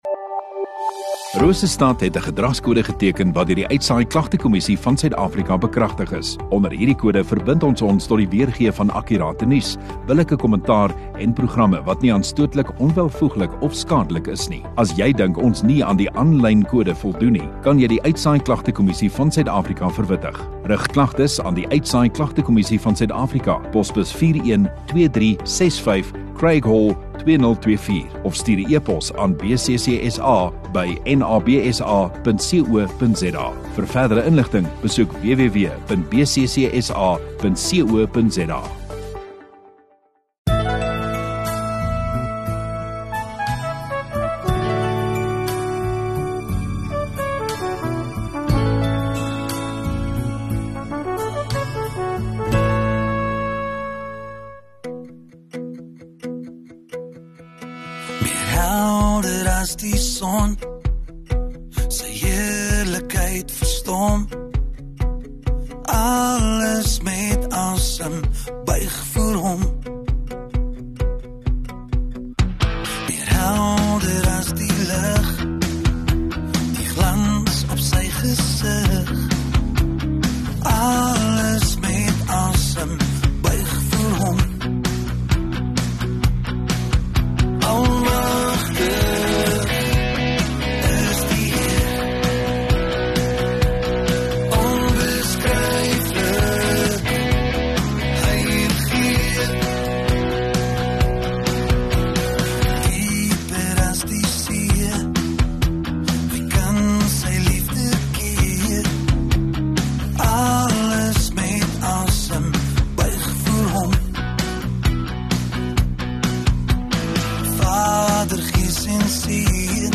23 Sep Saterdag Oggenddiens